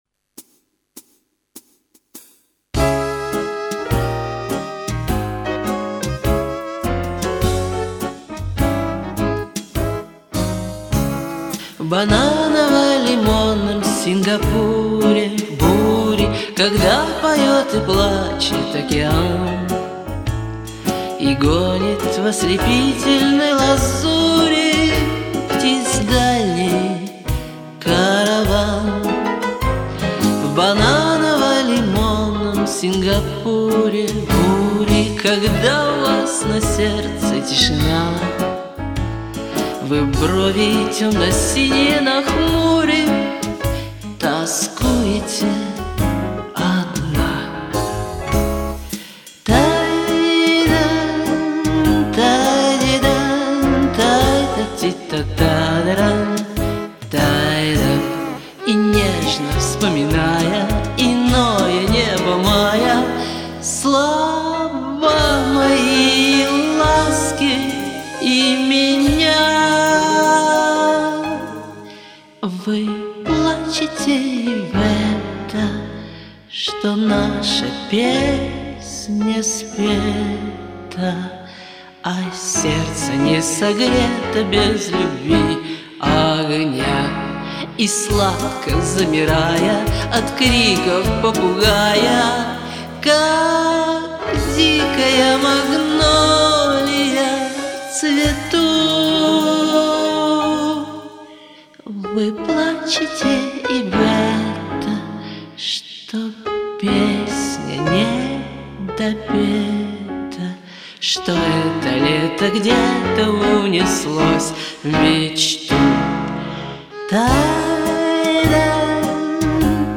а ты такая нежно-лиричная тут..."вы плачете..." поешь так
Нуу, как-то с блатным таким уклонизЪмом звучишь..